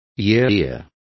Also find out how mofa is pronounced correctly.